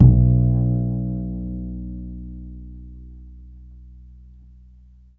DBL BASS C.1.wav